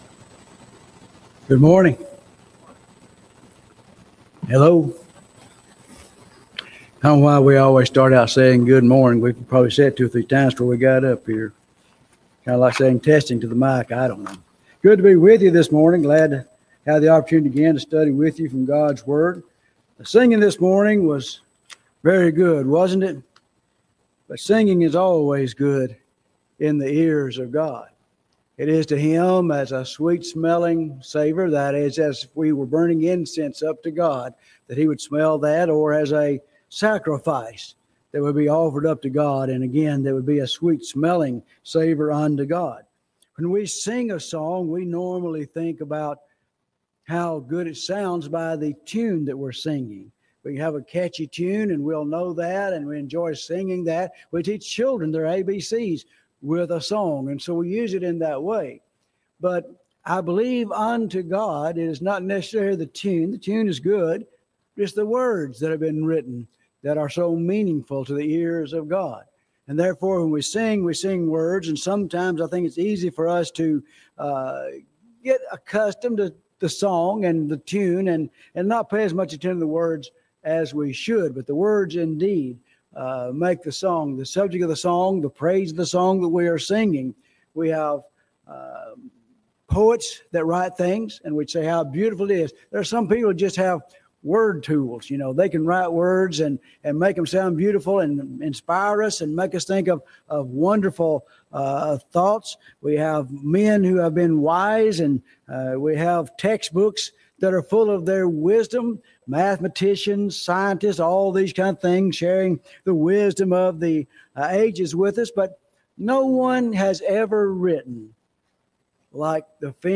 Cedar Grove Church of Christ August 17 2025 AM Sunday Sermon - Cedar Grove Church of Christ